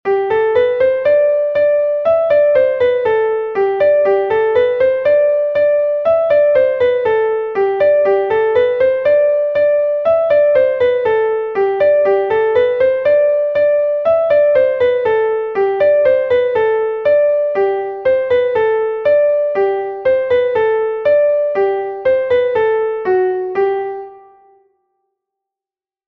is a Rond from Brittany